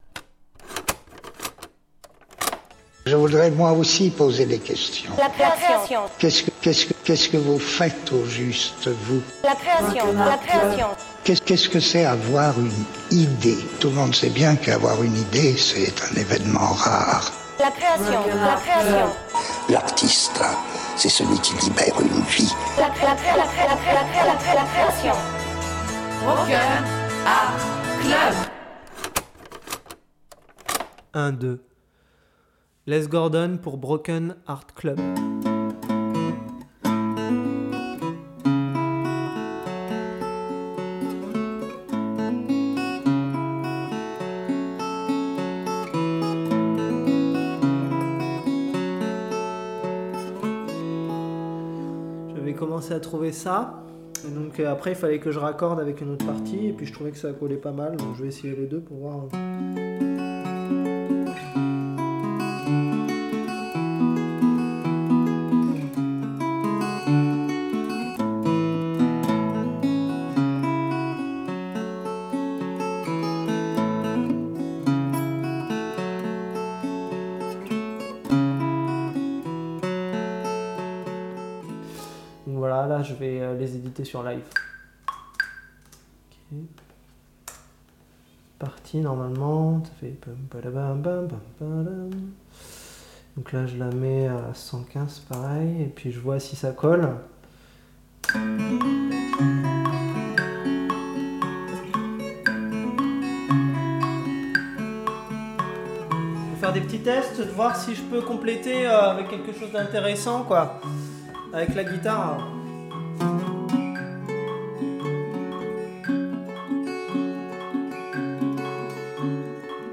construit ses compositions à coups de samples
Une guitare